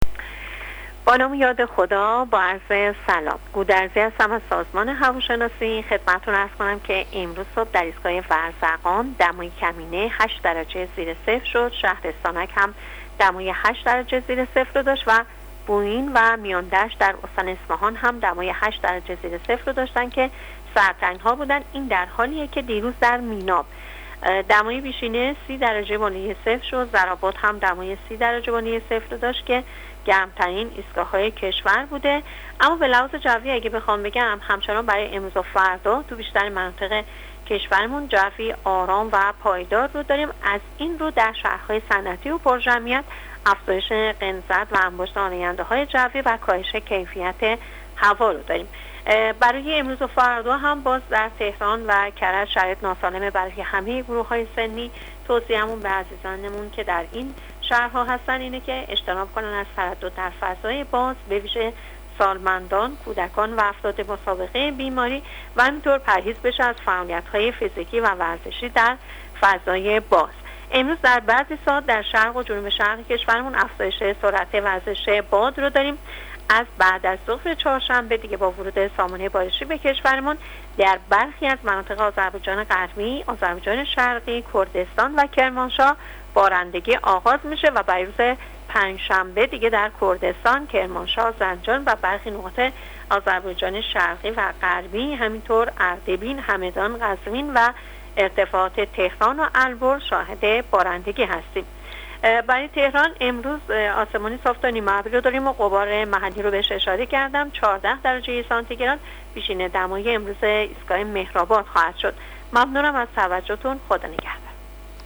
کارشناس سازمان هواشناسی کشور در گفت‌وگو با رادیو اینترنتی وزارت راه‌وشهرسازی، آخرین وضعیت آب‌و‌هوای کشور را تشریح کرد.
گزارش رادیو اینترنتی از آخرین وضعیت آب‌‌و‌‌‌هوای شانزدهم آذر؛